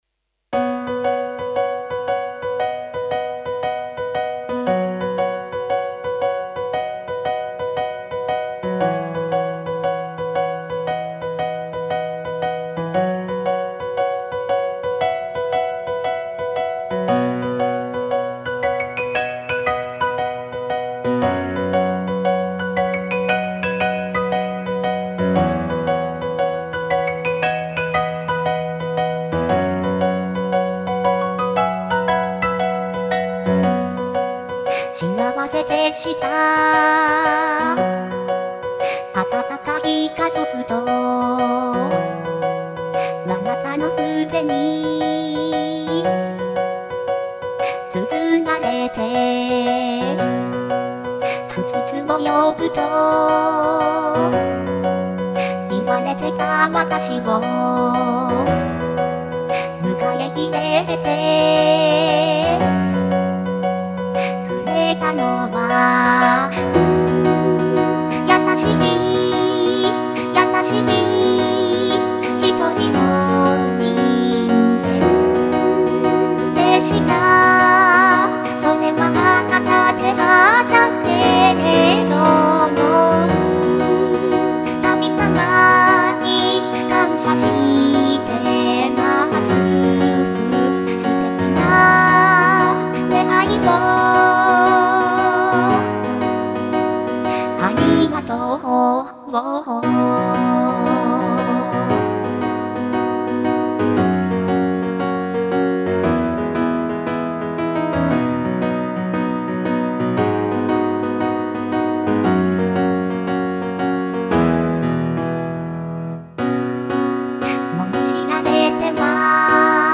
しっとりと優しいピアノバラード曲です。